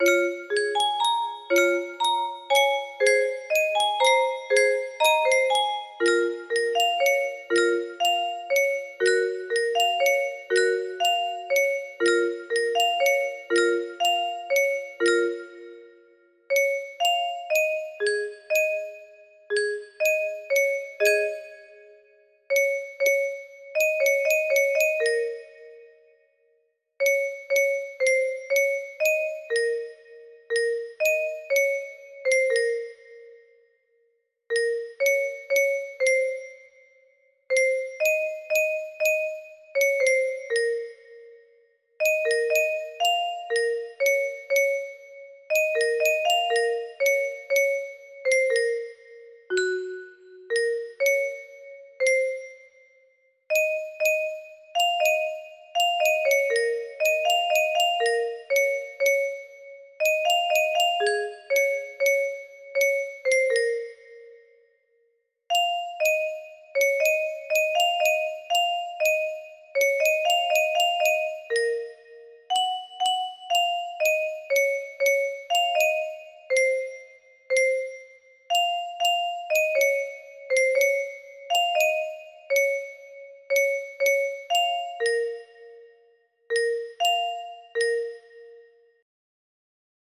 music boxes